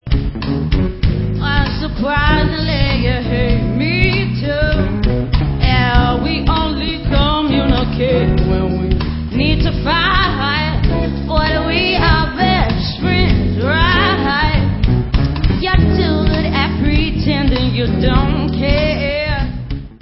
(Leicester Summer Sundae 2004)
Pop